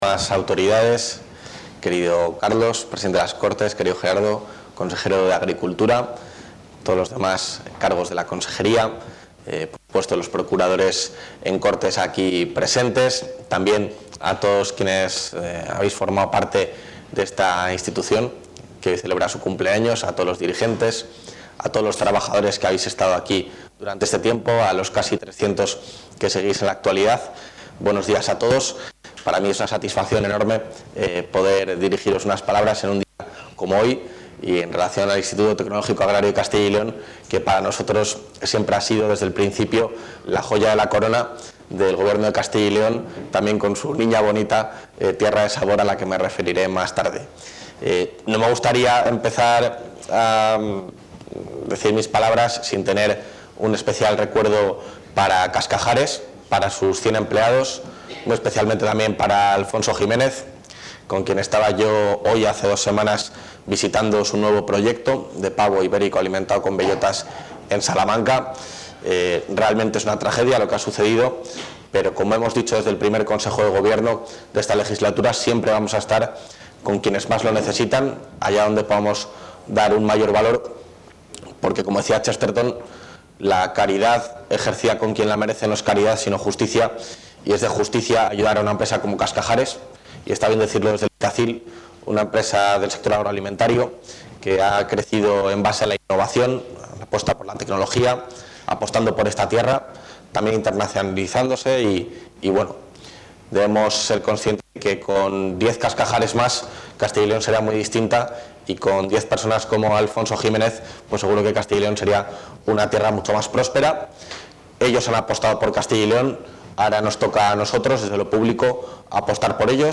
Intervención del vicepresidente.
El vicepresidente de la Junta de Castilla y León, Juan García-Gallardo, ha presidido hoy el inicio de los actos conmemorativos del veinte aniversario del Instituto Tecnológico Agrario de Castilla y León (Itacyl); una institución que ha calificado como “proyecto de éxito” y a la que se ha comprometido a seguir apoyando desde el Gobierno autonómico.